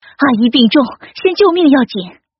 分段配音